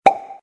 achievement-unlocked.ogg